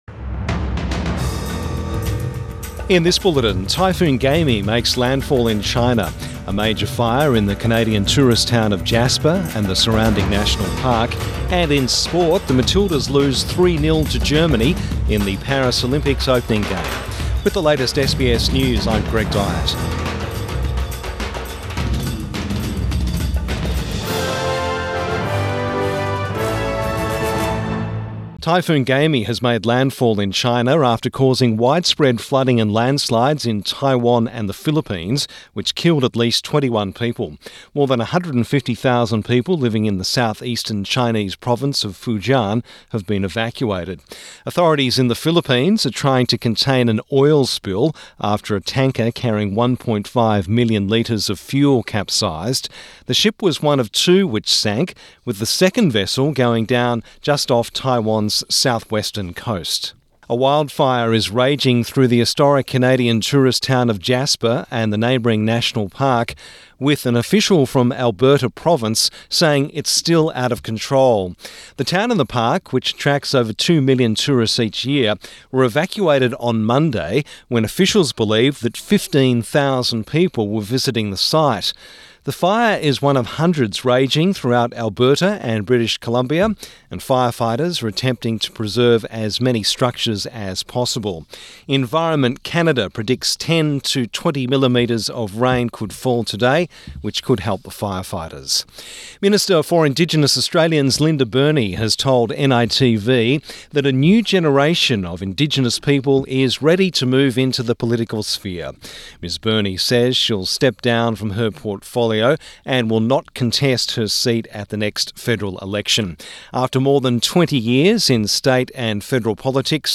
Morning News Bulletin 26 July 2024